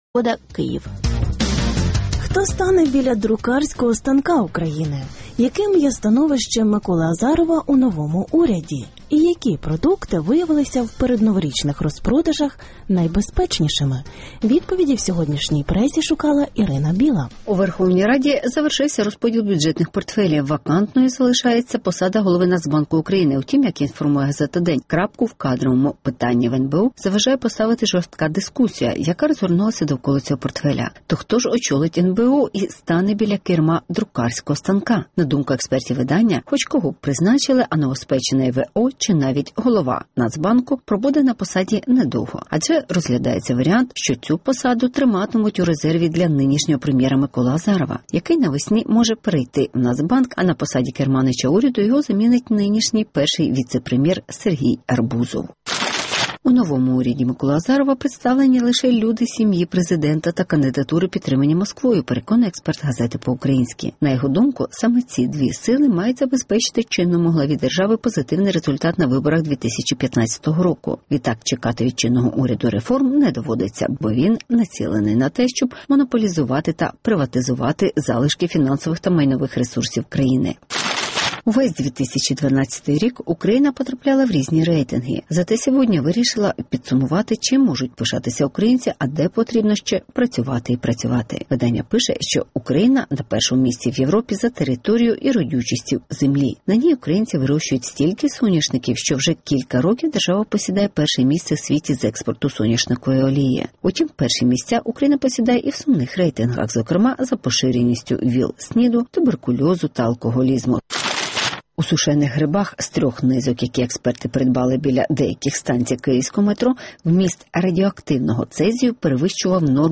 Коли Арбузов замінить Азарова? (Огляд преси)